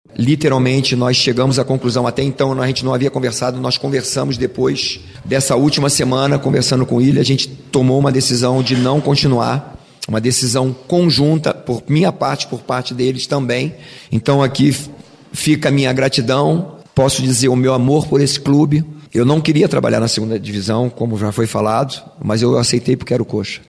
No início da entrevista coletiva que marcou esta despedida